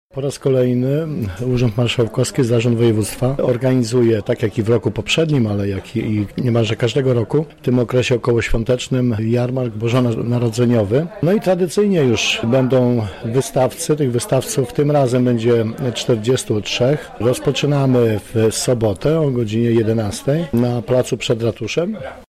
• mówi Grzegorz Kapusta, wicemarszałek województwa lubelskiego.